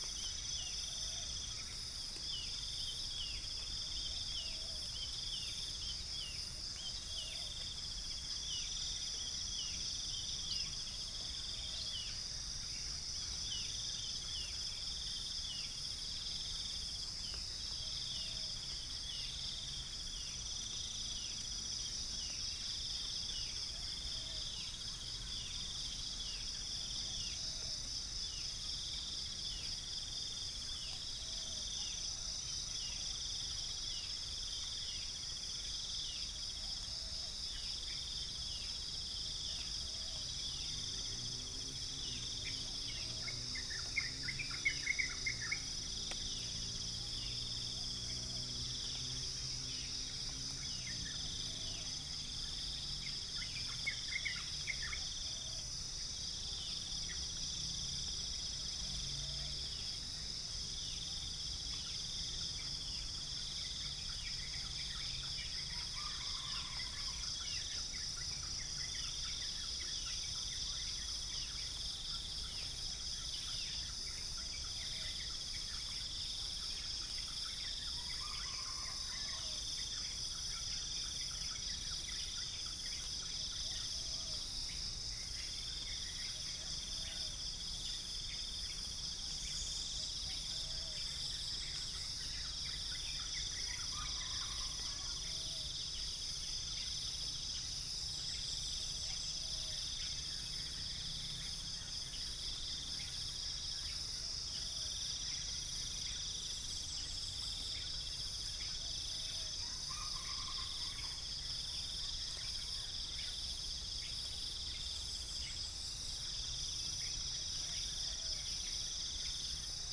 Upland plots dry season 2013
Spilopelia chinensis
Centropus sinensis
Gallus gallus domesticus
3 - amphibian
Orthotomus sericeus
Pycnonotus goiavier